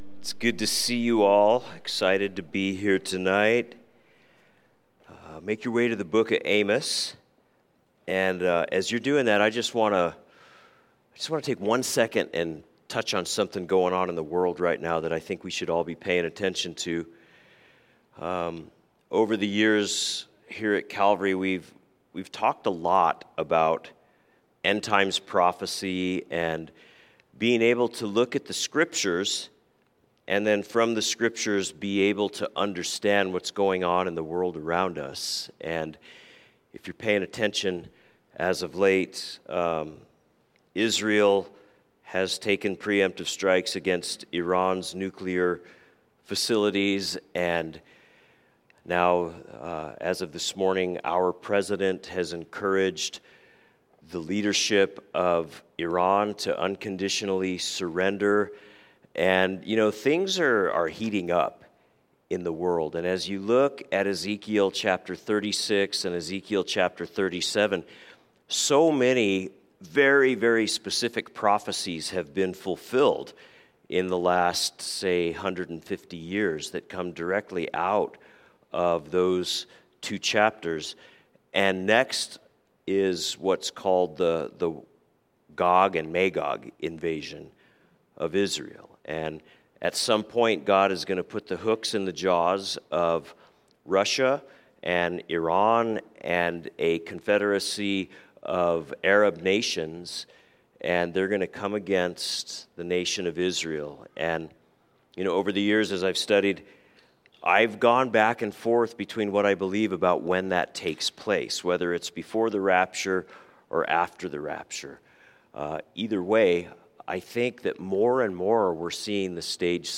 A message from the service "Wednesday Evening."